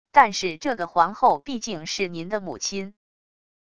但是这个皇后毕竟是您的母亲wav音频生成系统WAV Audio Player